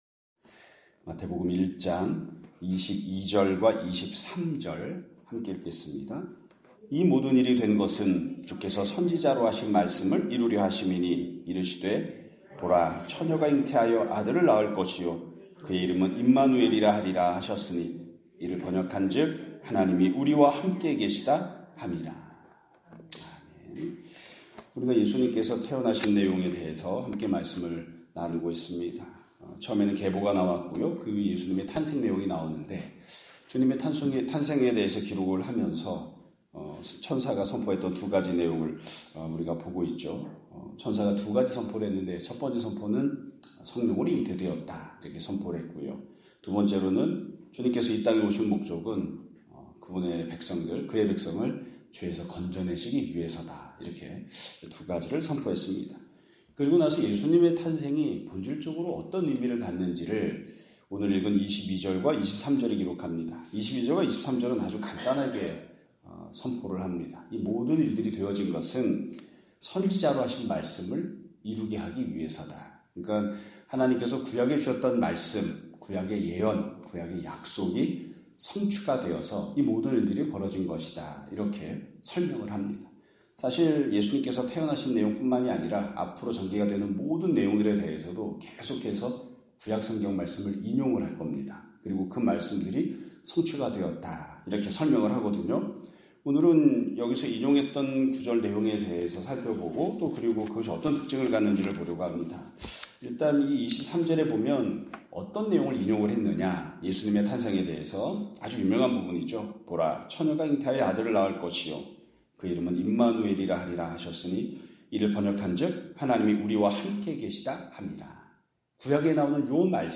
2025년 3월 26일(수요일) <아침예배> 설교입니다.